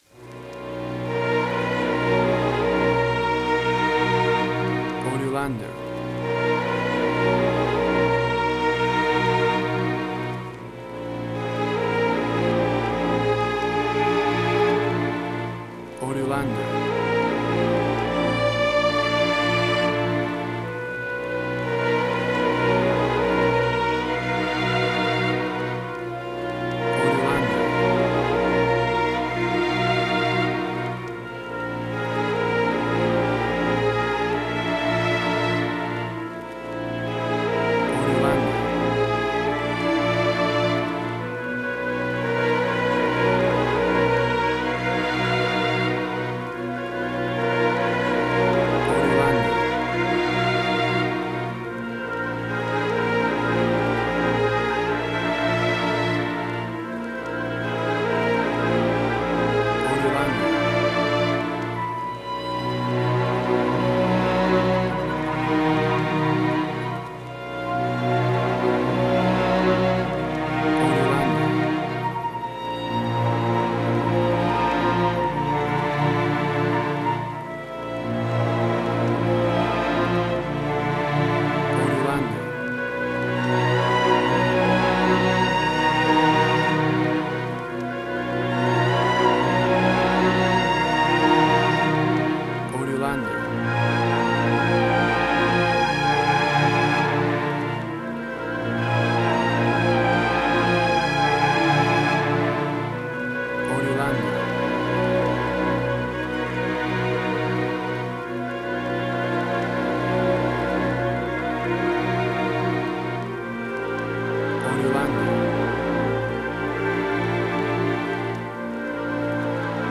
Modern Film Noir.
WAV Sample Rate: 16-Bit stereo, 44.1 kHz